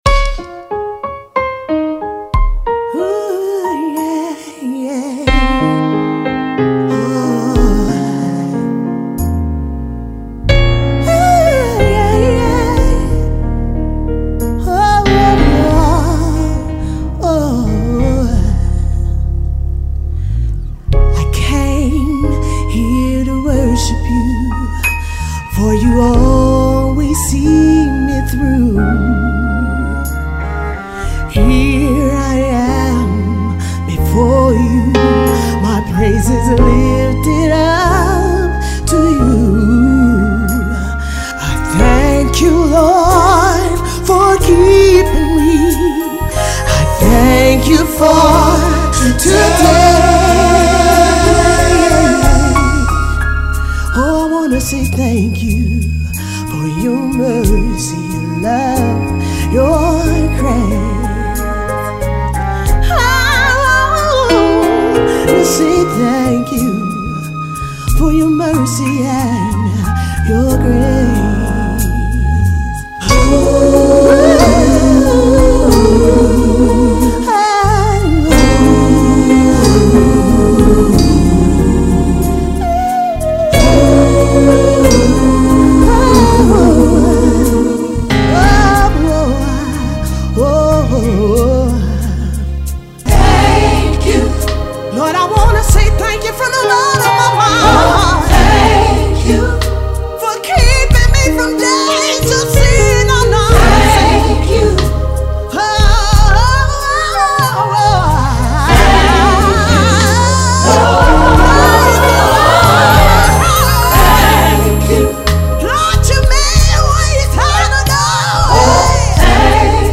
It is worship in a soulful, uplifting testimony.